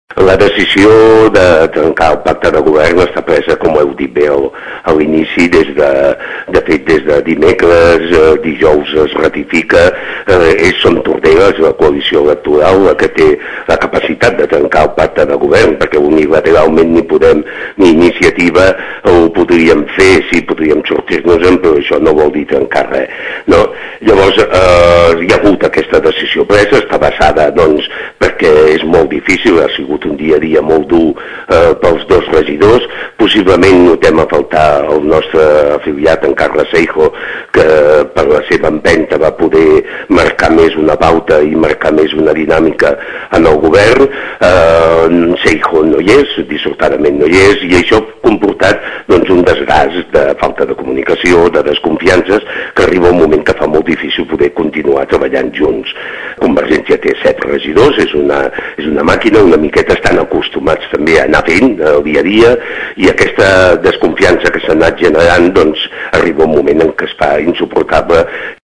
en una entrevista als serveis informatius d’aquesta emissora